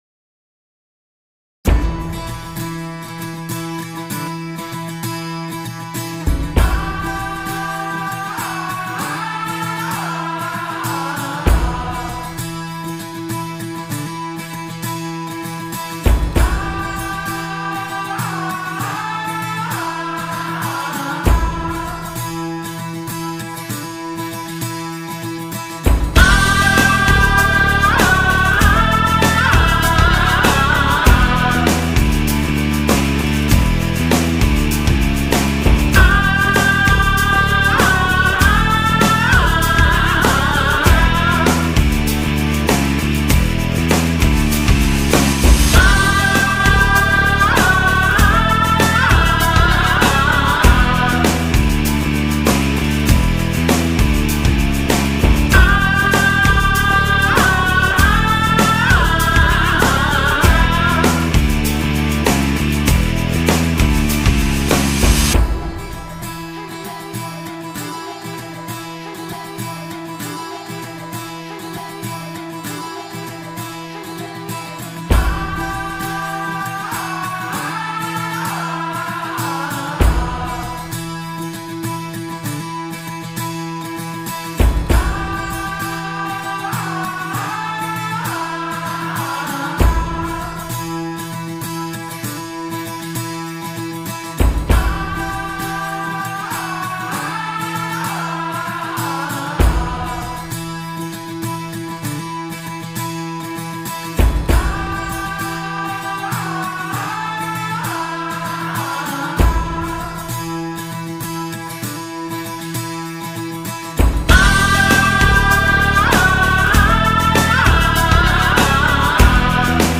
dizi müziği, duygusal heyecan enerjik fon müziği.